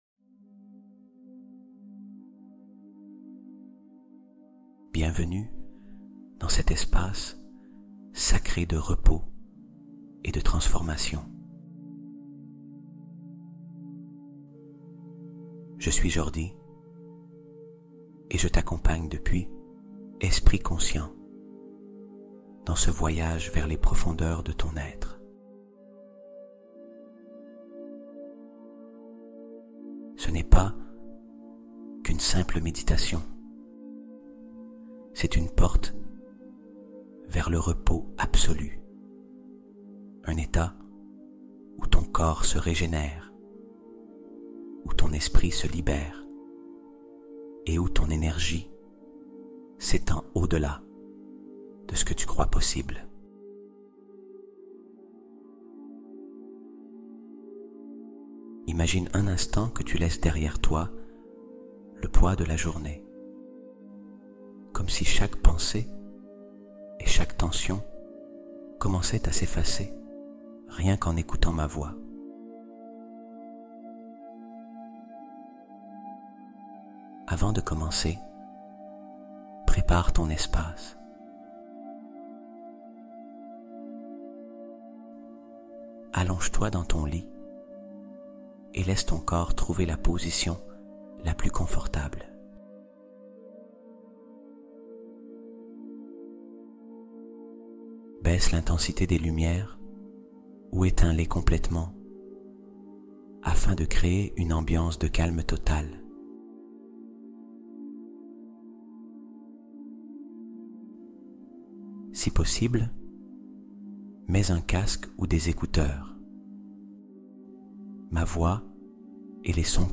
Méditation pour changer ta vie aujourd’hui